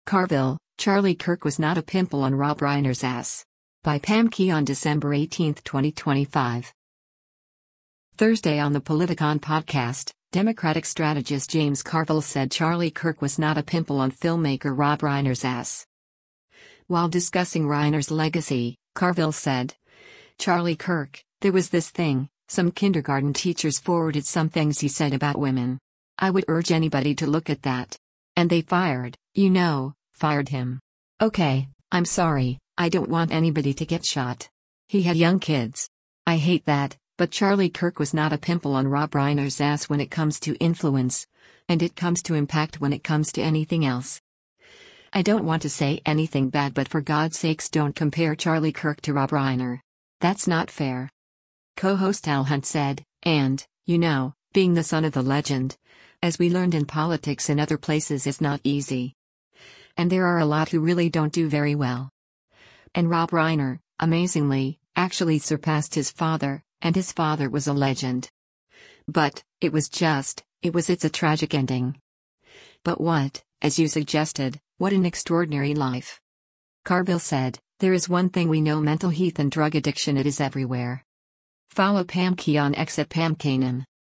Thursday on the “Politicon” podcast, Democratic strategist James Carville said Charlie Kirk “was not a pimple” on filmmaker Rob Reiner’s ass.